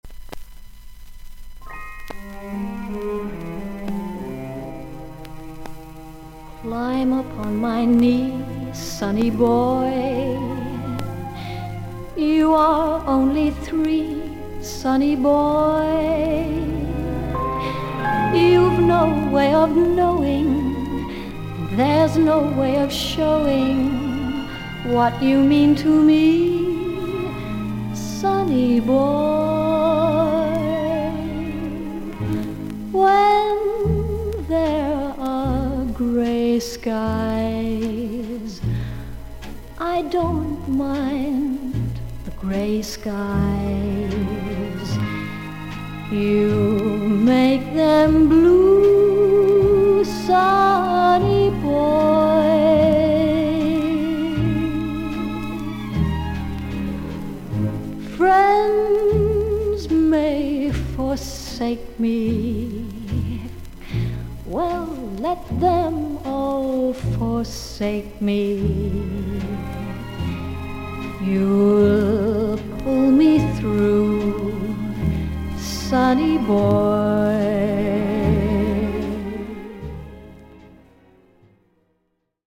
A2序盤に横に近い角度で斜めに3mmのキズ、曲間から7回ほどパチノイズあり。
ほかはVG+〜VG++:少々軽いパチノイズの箇所あり。少々サーフィス・ノイズあり。クリアな音です。
スウィングやスロウのアレンジでチャーミングに歌っています。